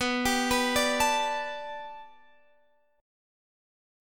Bm7#5 chord